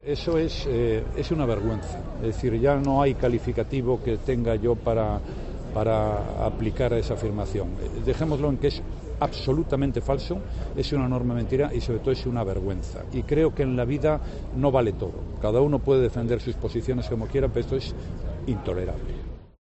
Rajoy ha respondido a las palabras de Rovira en declaraciones a los periodistas en Gotemburgo, donde ha participado en una cumbre social de los líderes de la UE.